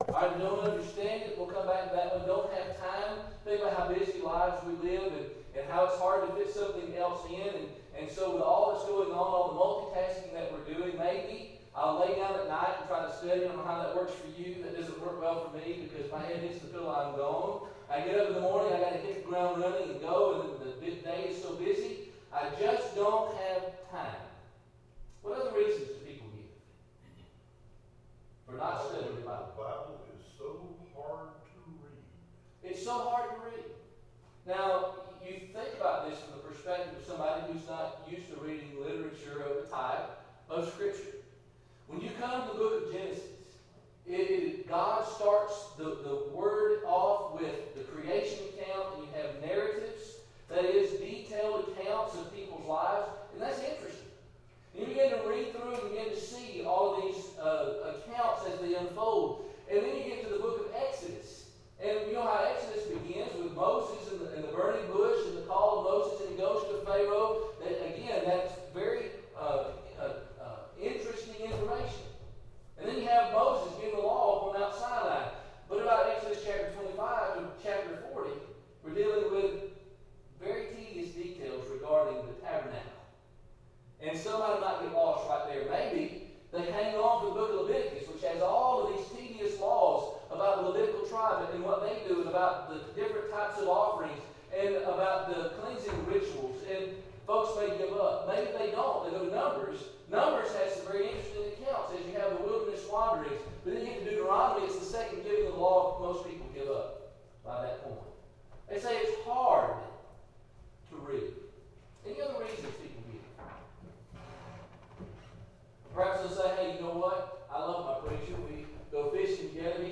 Great Text of the Bible Service Type: Gospel Meeting « State of our Nation Great Text of the Bible